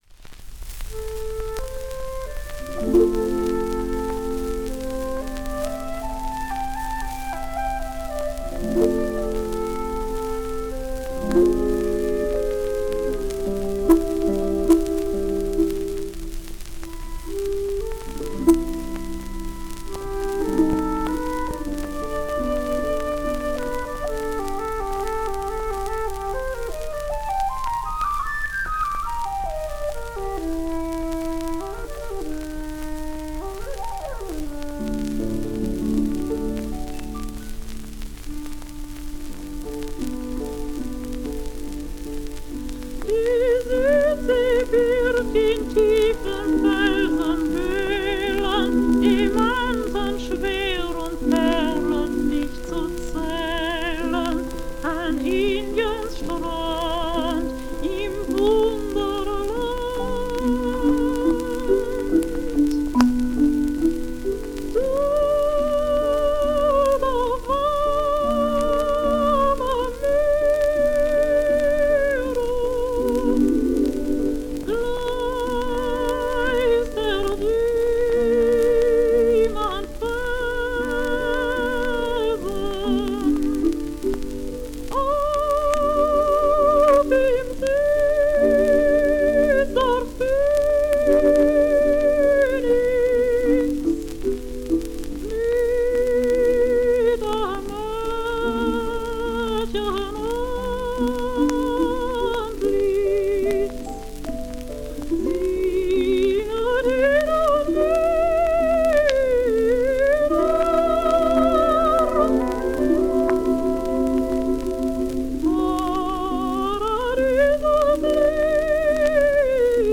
This recording proves that her voice oscillated not only between soprano and mezzo-soprano, but that she even made a (creatively orchestrated) foray into tenor land.